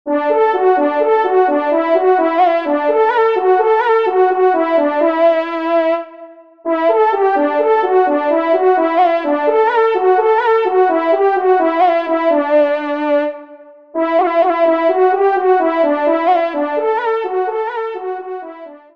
1e Trompe  (Ton de vénerie)